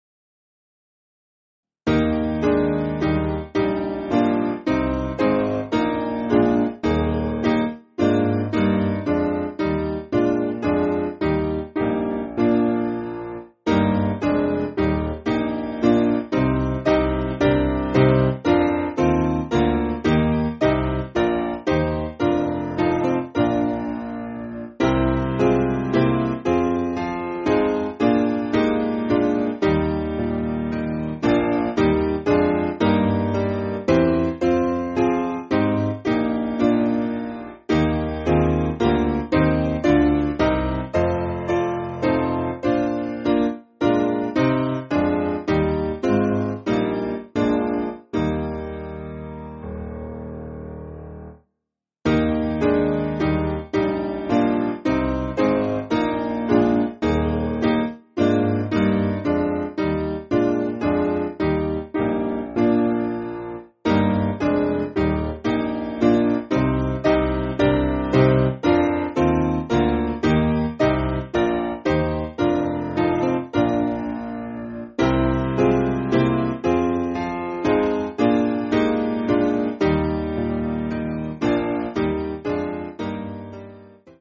Simple Piano
(CM)   5/Eb